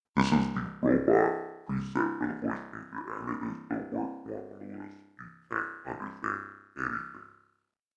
The SC3 comes with a voice changer feature.
Robot
robotvoice.mp3